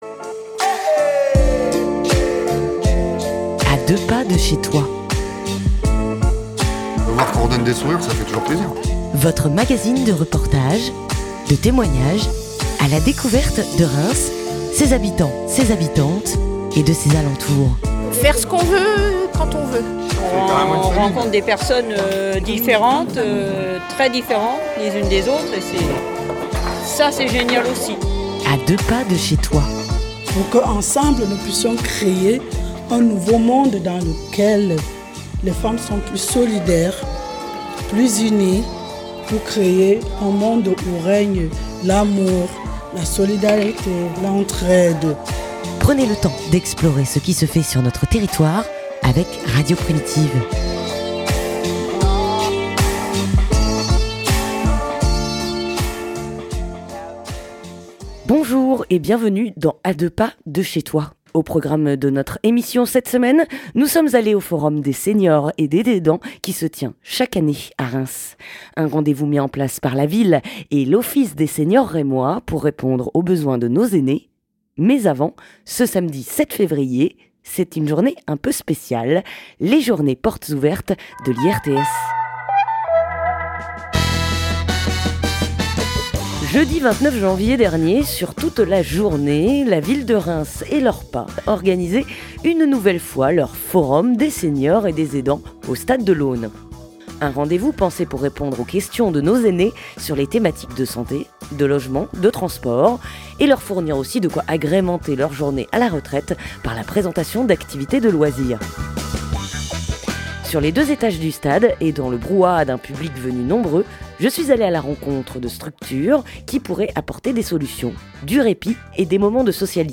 Retour sur le Forum des Séniors et des Aidants qui se tenait le jeudi 29 janvier à Reims. Au micro de Radio Primitive retrouvez la plateforme Bulle d'R, la Maison de la Nutrition et l'association Siel Bleu.